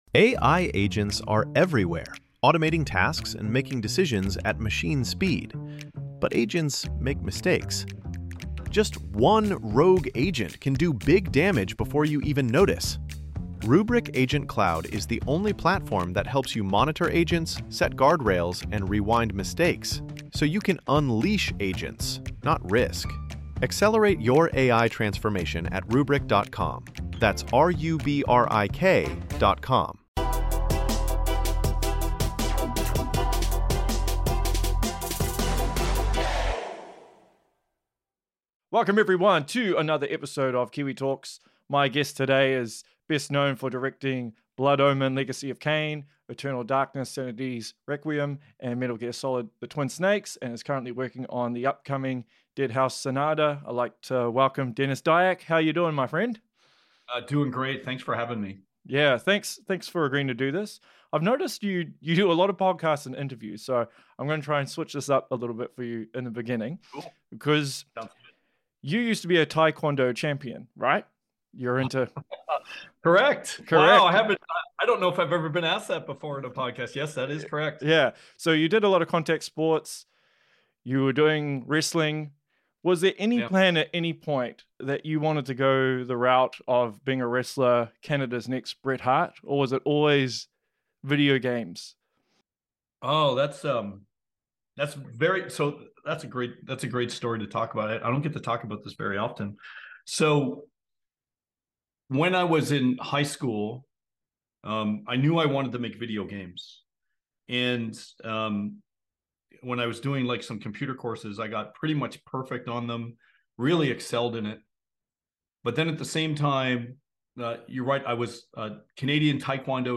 #150 - Denis Dyack Interview (Eternal Darkness, Twin Snakes, Deadhaus Sonata, A.I., Narrative, Martial Arts etc.) ~ Kiwi Talkz Podcast